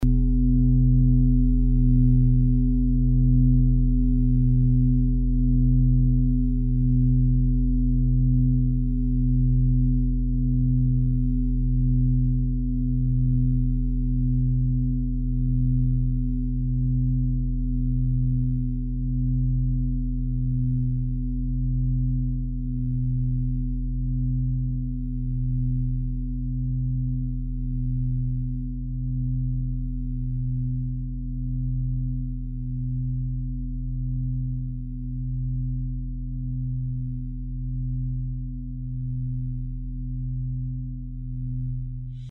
Fuß-Klangschale Nr.8
Diese große Klangschale wurde in Handarbeit von mehreren Schmieden im Himalaya hergestellt.
(Ermittelt mit dem Gummischlegel)
Sonnenton:
fuss-klangschale-8.mp3